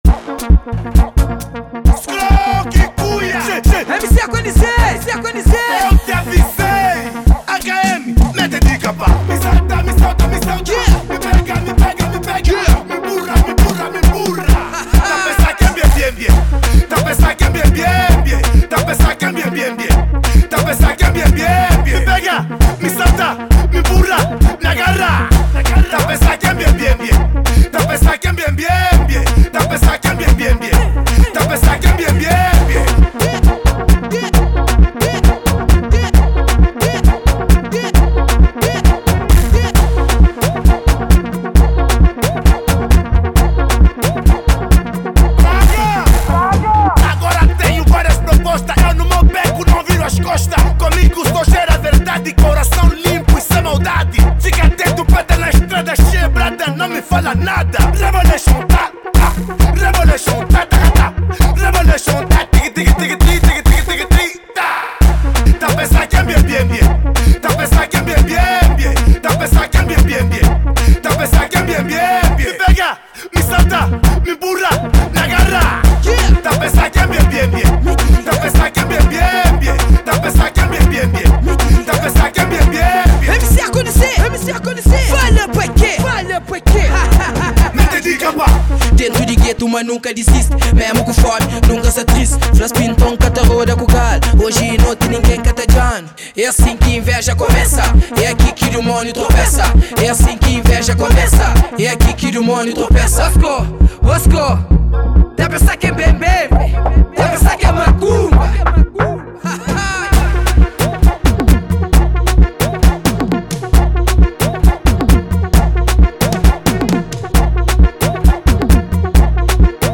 Genero: Kuduro